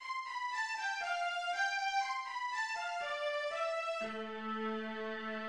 It is not in typical scherzo form, however, being in 2/4 time and in sonata form, without a trio.
1 Primary theme C major and E major Primary theme consisting of three different periods (ordered 1–2–3–1)